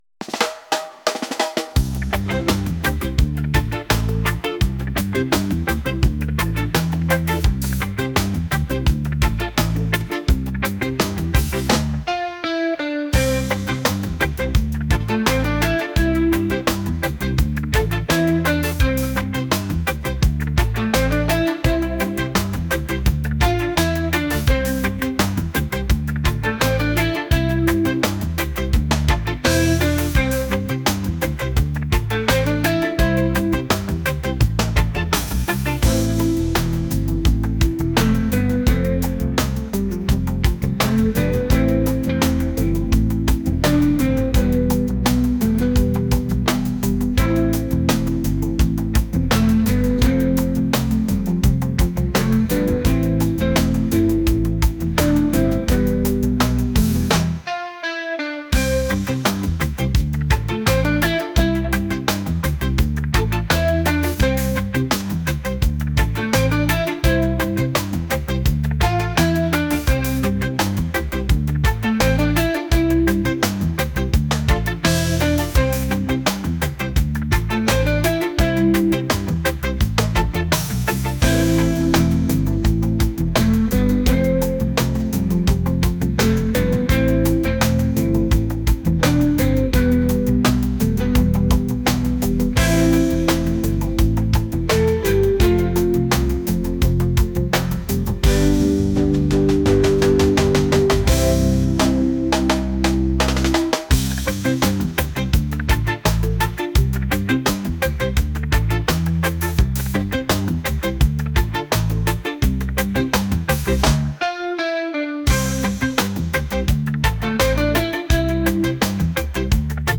reggae | folk | world